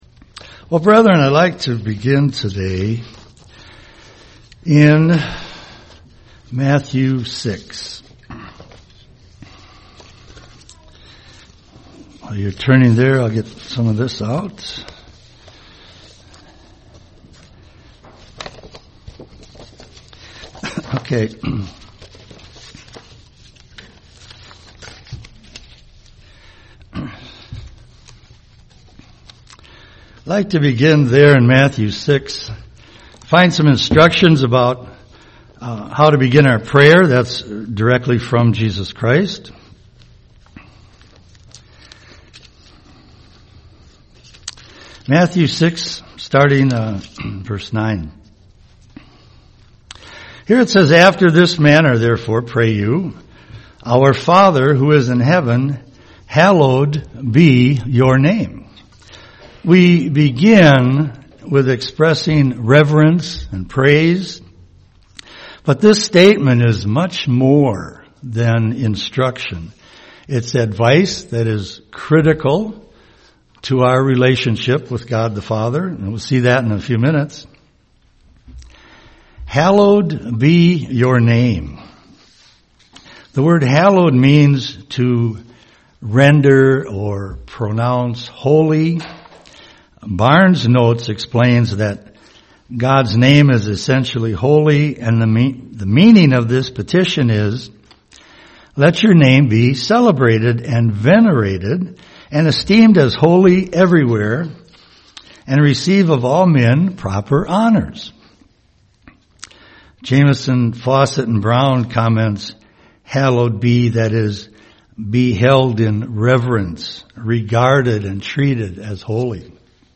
Given in Twin Cities, MN
UCG Sermon praise to God Studying the bible?